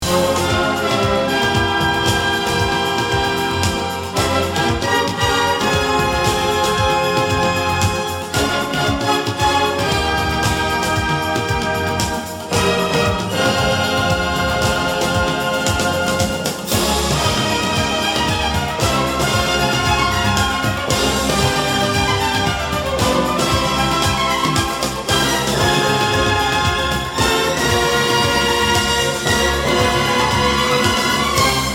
key: F - minor